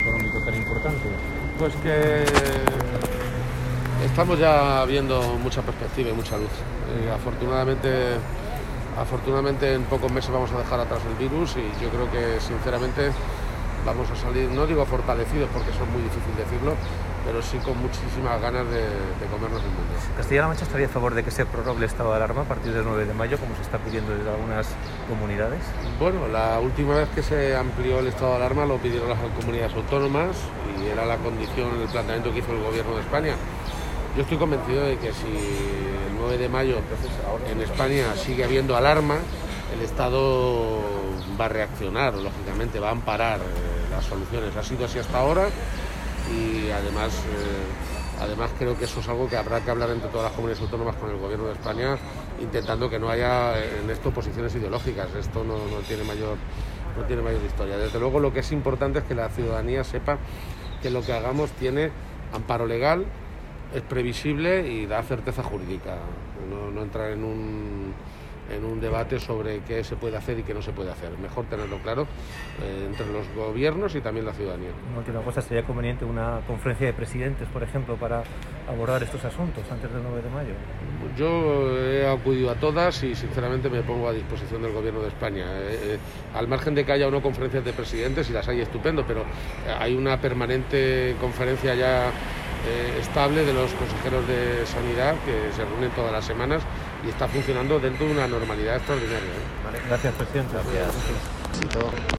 El jefe del Ejecutivo regional se ha pronunciado así esta mañana, en Madrid, momentos antes de participar en el foro ‘Wake Up Spain!’ que organiza El Español.